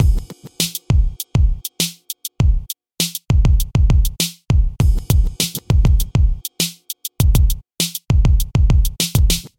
描述：速度：100bpm 只是一个基本的断奏。
Tag: 100 bpm Hip Hop Loops Drum Loops 1.62 MB wav Key : Unknown